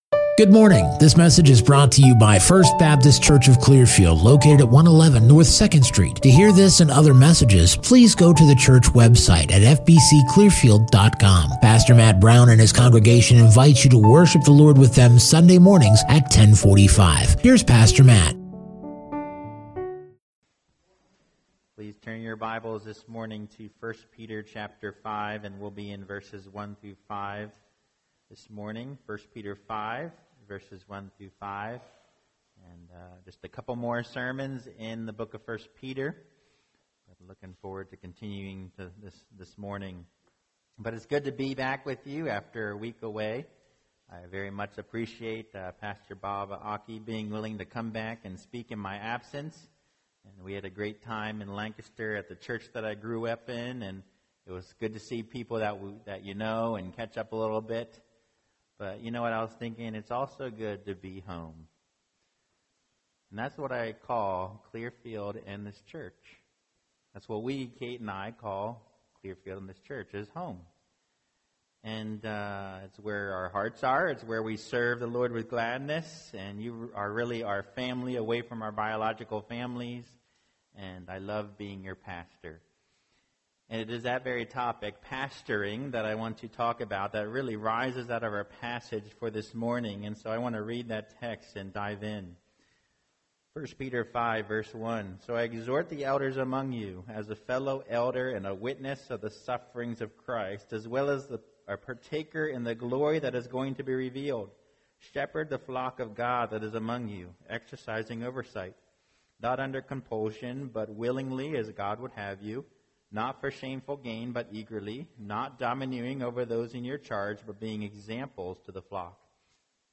2021 The Responsibility and Reward of an Elder Preacher